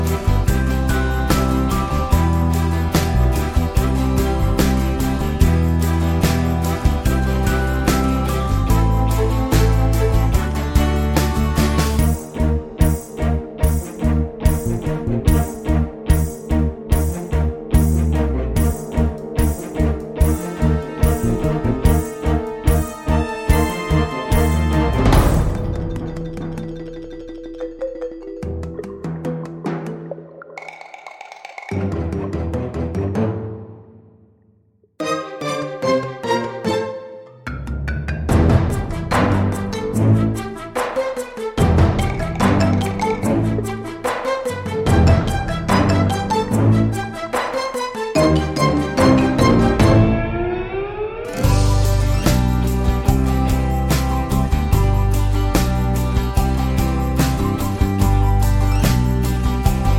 no Backing Vocals Soundtracks 3:05 Buy £1.50